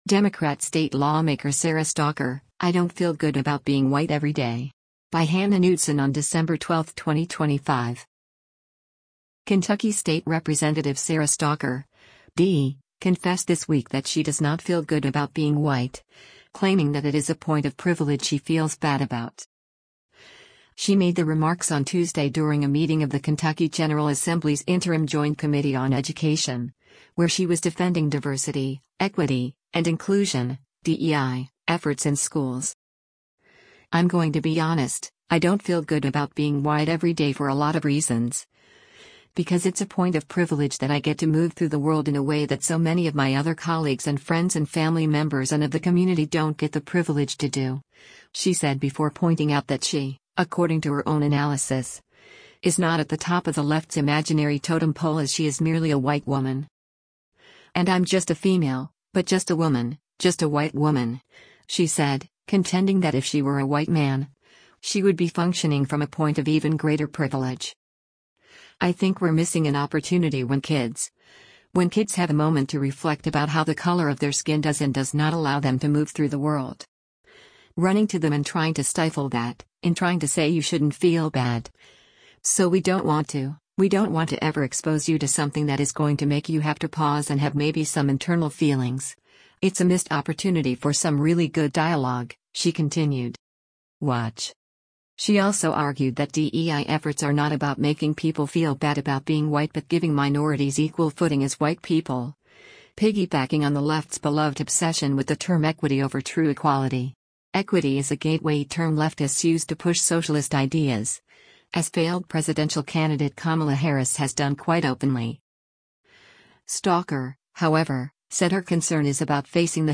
She made the remarks on Tuesday during a meeting of the Kentucky General Assembly’s Interim Joint Committee on Education, where she was defending Diversity, Equity, and Inclusion (DEI) efforts in schools.